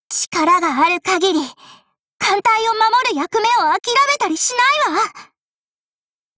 贡献 ） 协议：Copyright，人物： 碧蓝航线:格伦维尔·META语音 您不可以覆盖此文件。